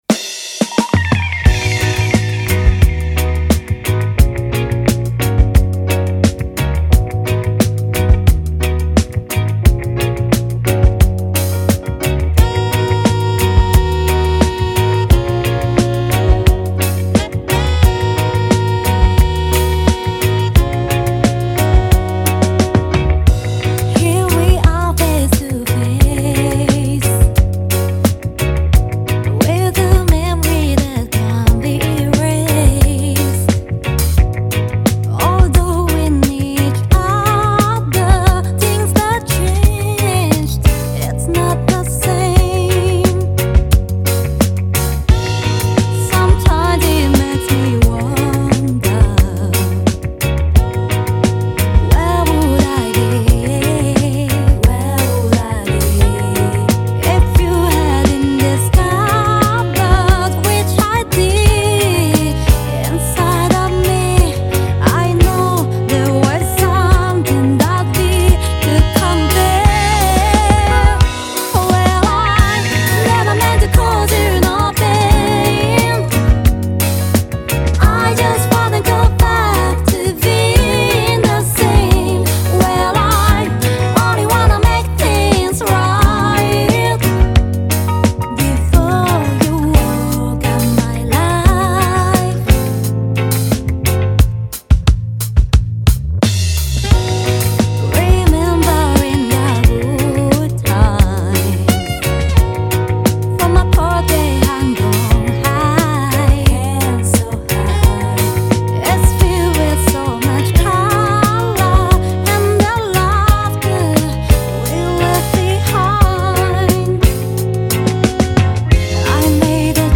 ジャンル(スタイル) REGGAE / R&B / DISCO / JAPANESE